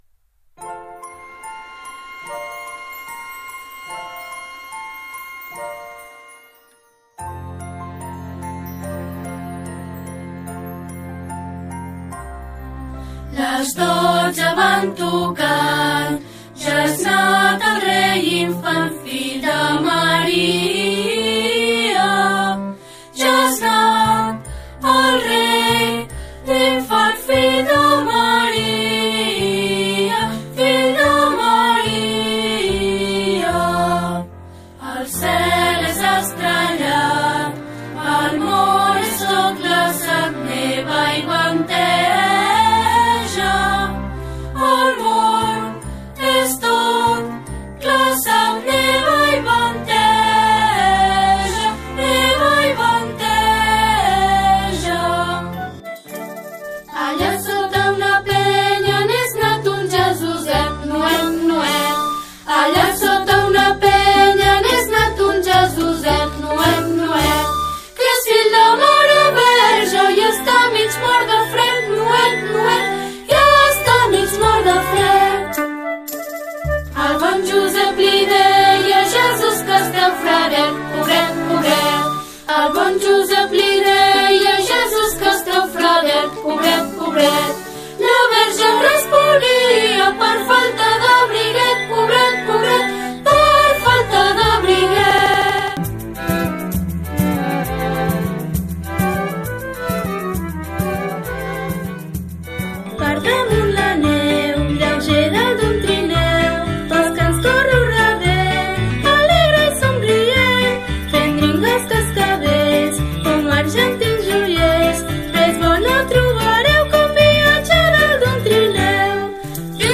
Aquí teniu l’enllaç del “mix” de nadales que cantareu el dia del concert.